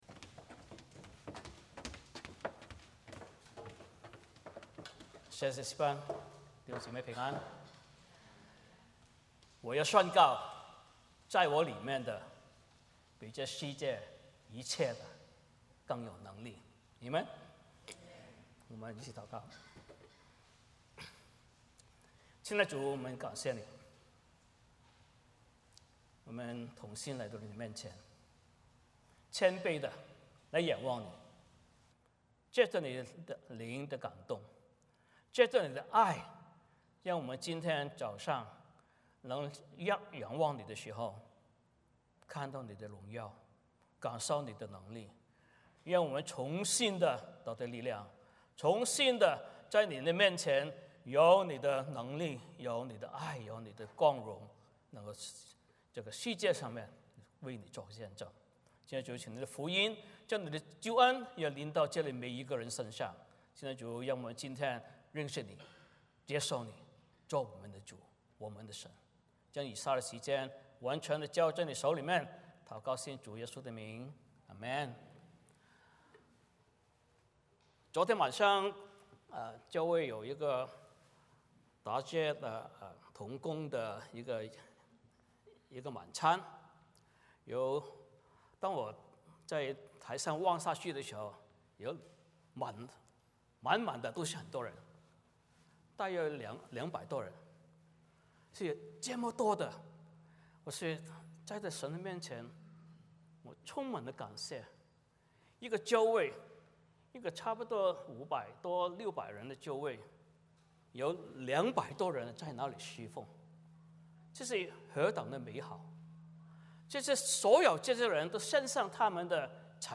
哥林多前书 13:1-13 Service Type: 主日崇拜 欢迎大家加入我们的敬拜。